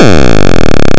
Im Anhang habe ich den Versuch mit linearer Offzeit mit Octave erzeugt. Unter klanglichen Aspekten würde ich sagen: Ausbaufähig ;-) Beitrag melden Bearbeiten Löschen Markierten Text zitieren Antwort Antwort mit Zitat
pulseChirp.wav